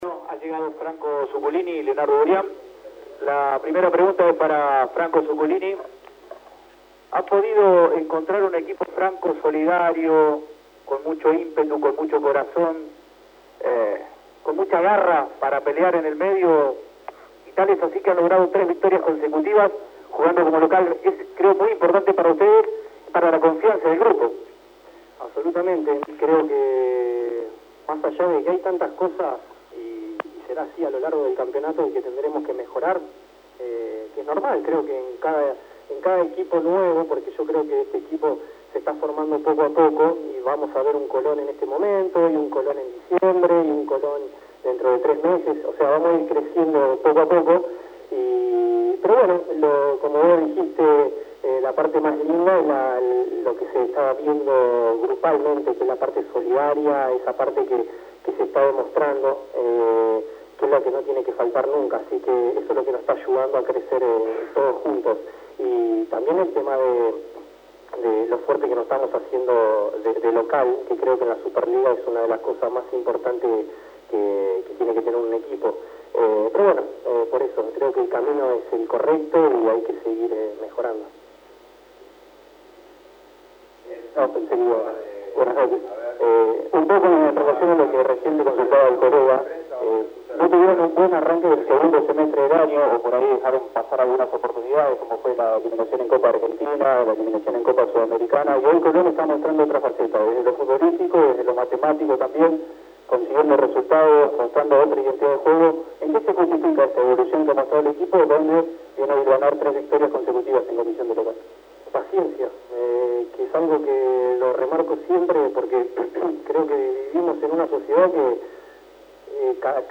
• Conferencia de prensa de Franco Zuculini y Leonardo Burián.